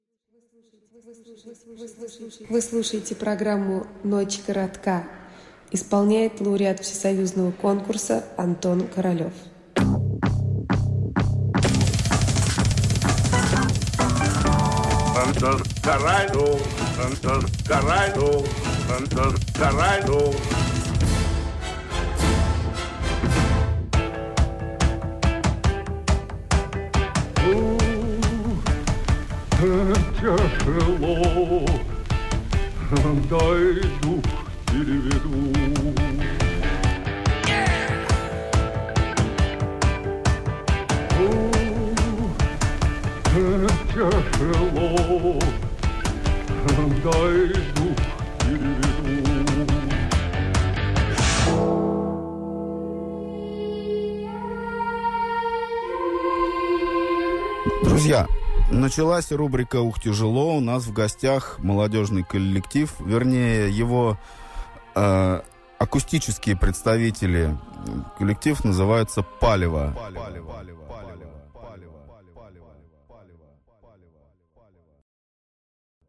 Между треками для полноты картины присутсвтуют небольшие бредовые вставочки.